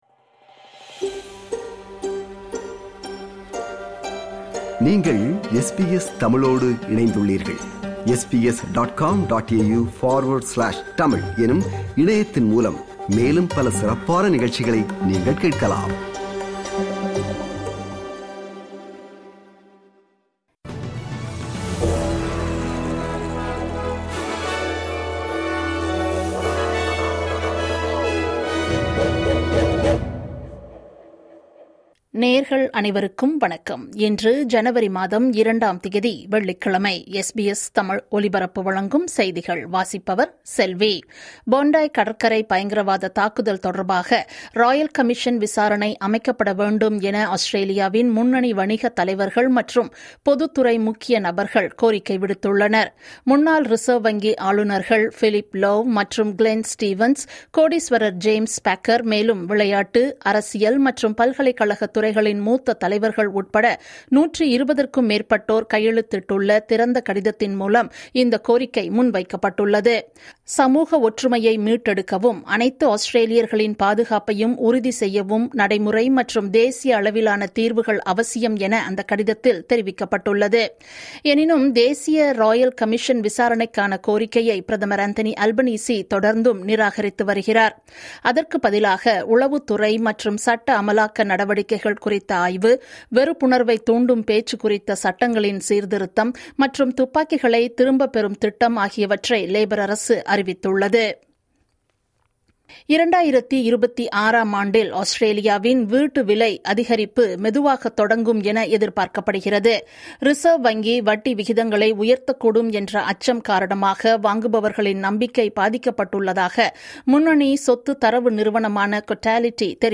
SBS தமிழ் ஒலிபரப்பின் இன்றைய (வெள்ளிக்கிழமை 02/01/2026) செய்திகள்.